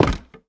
Minecraft Version Minecraft Version 25w18a Latest Release | Latest Snapshot 25w18a / assets / minecraft / sounds / entity / armorstand / break1.ogg Compare With Compare With Latest Release | Latest Snapshot
break1.ogg